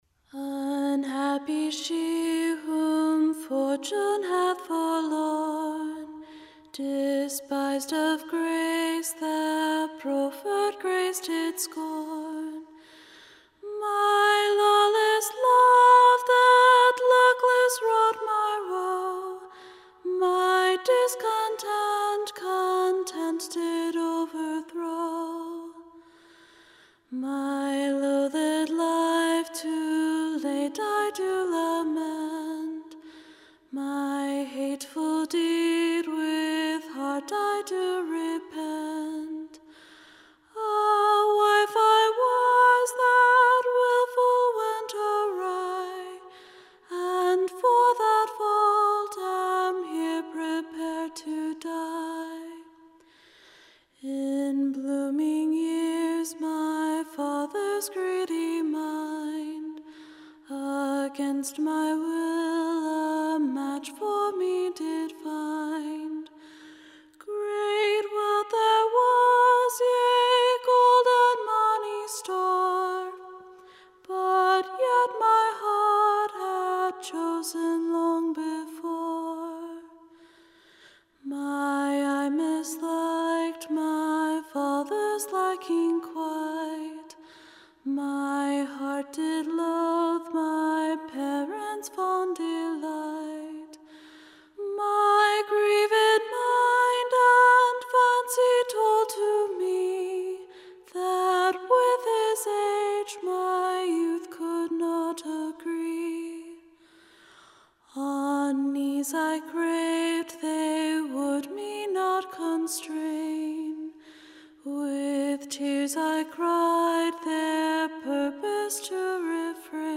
Ballad